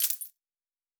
Coins 13.wav